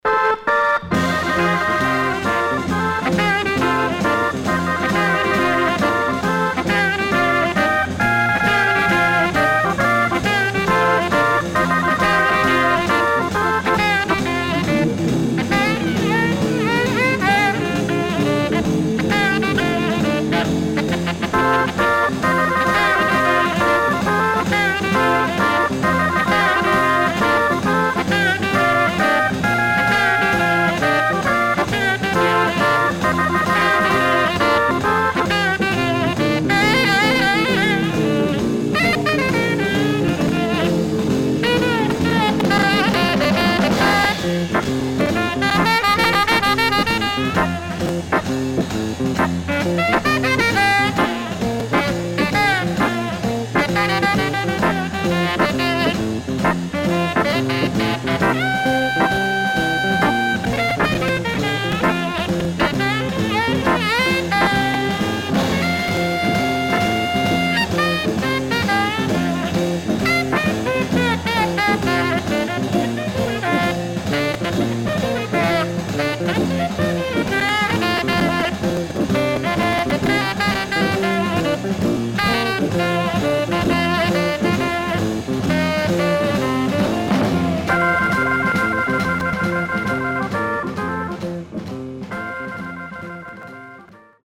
SIDE A:所々チリノイズ入ります。